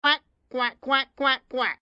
AV_duck_long.ogg